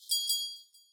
Ryuuzan_shop_door_bell00
bell ding door shop sound effect free sound royalty free Sound Effects